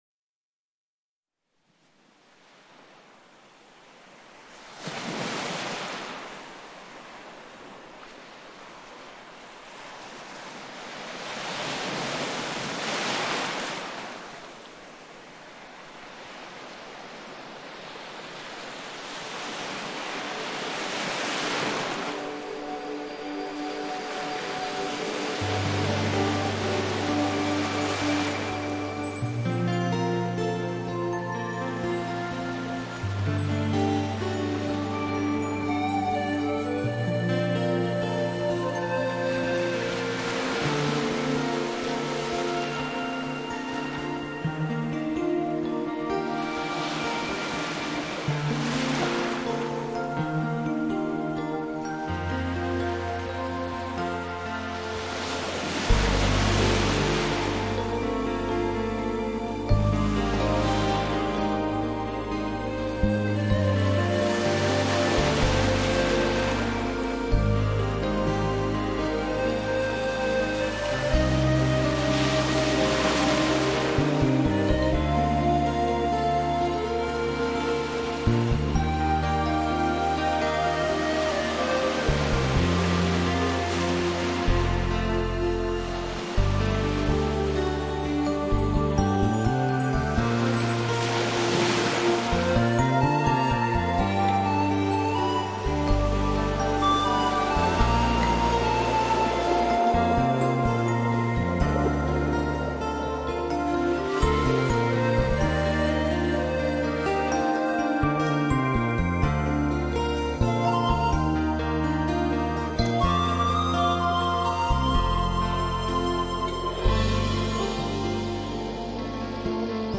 悠长的汽笛声